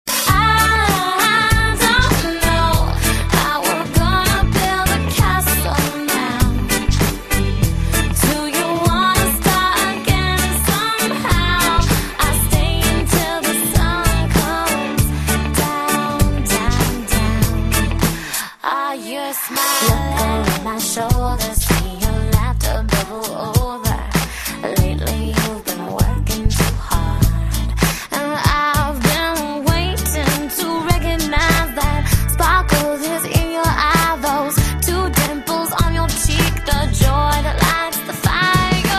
M4R铃声, MP3铃声, 欧美歌曲 85 首发日期：2018-05-15 20:39 星期二